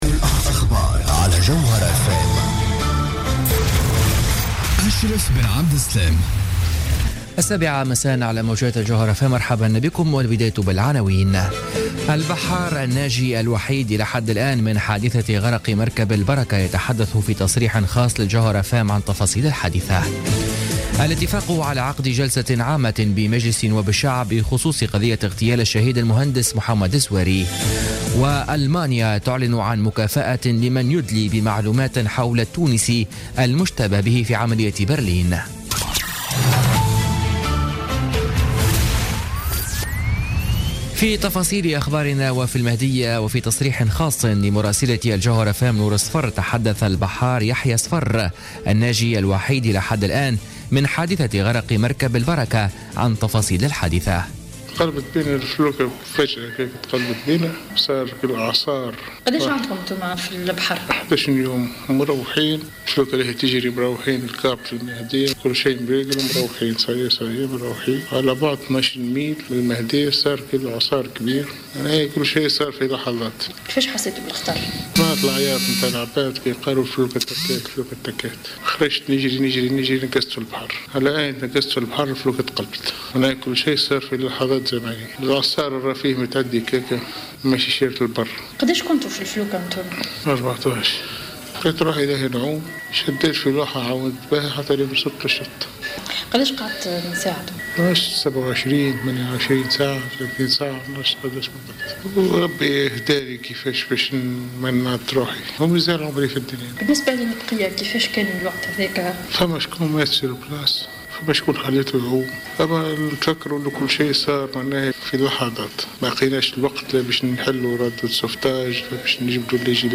نشرة أخبار السابعة مساء ليوم الأربعاء 21 ديسمبر 2016